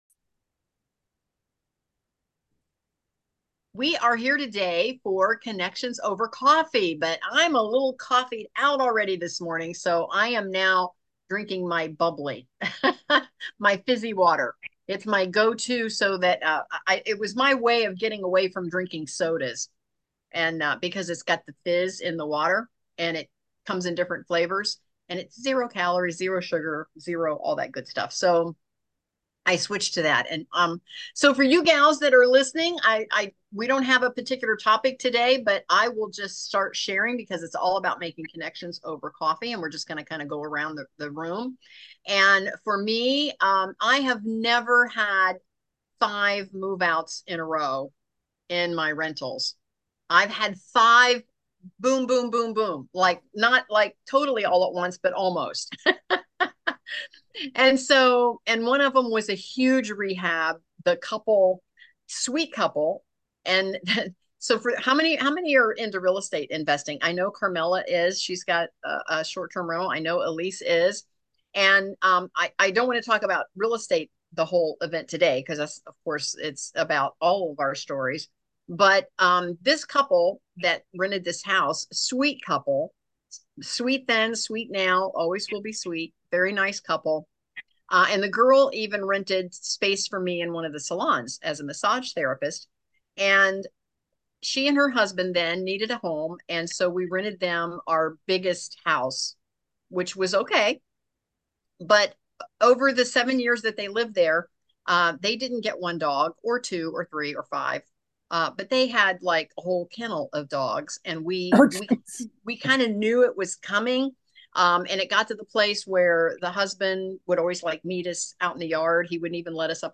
a virtual party on Zoom that's all about connections, networking, laughter, and some good vibes